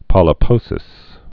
(pŏlə-pōsĭs)